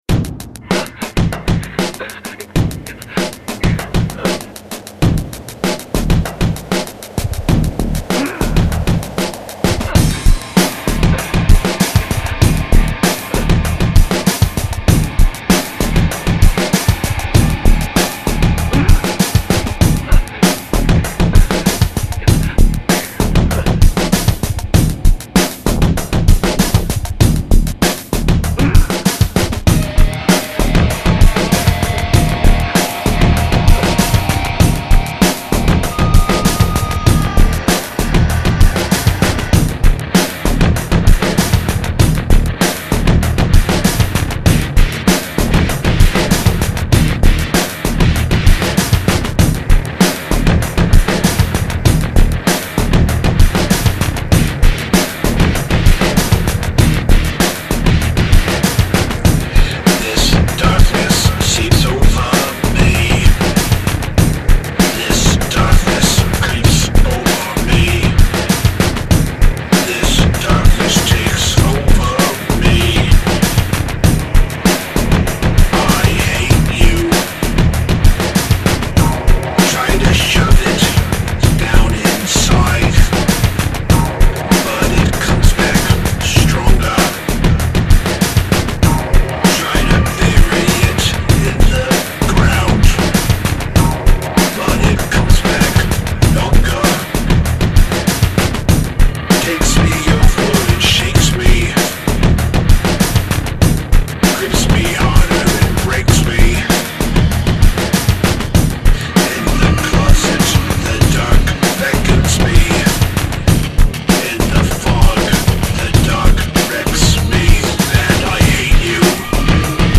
Dark Industrial Spooky Metal Gothic Electronica